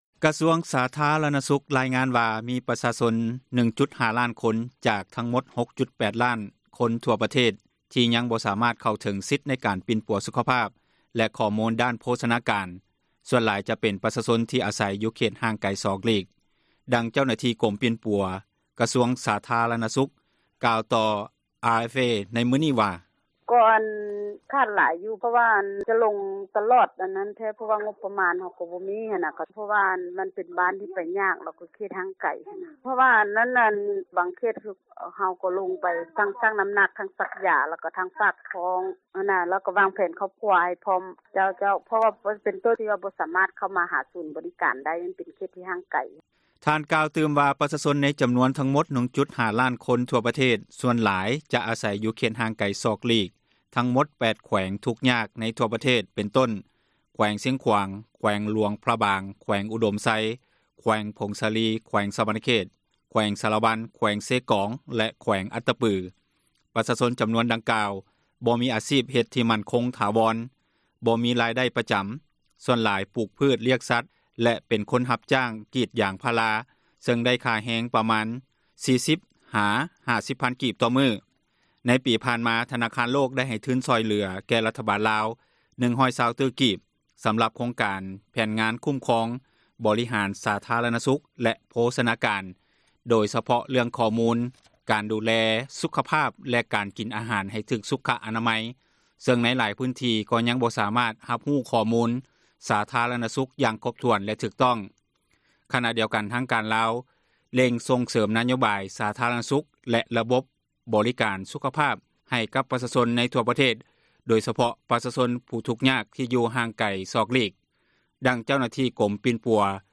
ກະຊວງສາທາຣະນະສຸຂ ຣາຍງານວ່າມີປະຊາຊົນ 1,5 ລ້ານຄົນ ຈາກທັງໝົດ 6,8 ລ້ານຄົນທົ່ວປະເທດ ຍັງບໍ່ສາມາດເຂົ້າເຖິງສິດໃນການ ປີ່ນປົວສຸຂພາບ ແລະຂໍ້ມູນດ້ານໂພຊນາການ ສ່ວນຫຼາຍຈະເປັນ ປະຊາຊົນທີ່ອາສັຍຢູ່ເຂດ ຫ່າງໄກສອກຫຼີກ. ດັ່ງ ເຈົ້າໜ້າທີ່ກົມປີ່ນປົວ ກະຊວງສາທາຣະນະສຸຂ ກ່າວຕໍ່ RFA ໃນມື້ນີ້ວ່າ: